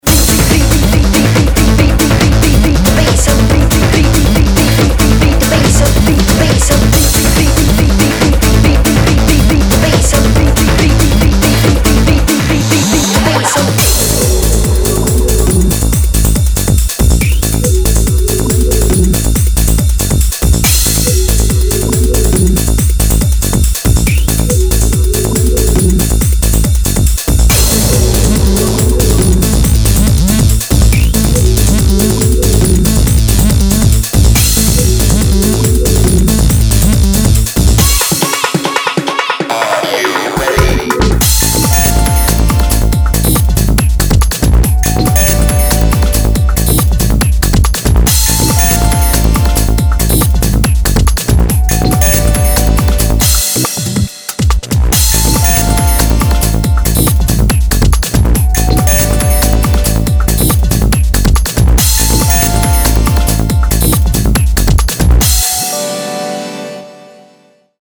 カテゴリー：ダンスコンピレーションCD